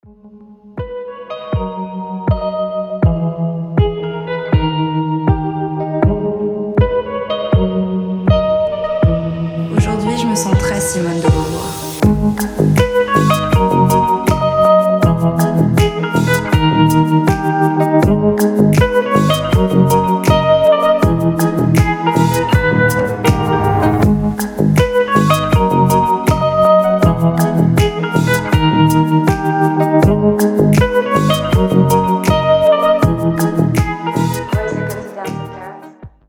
• Качество: 320, Stereo
спокойные
красивая мелодия
chillout
чувственные
медленные
Стиль: dance/downtempo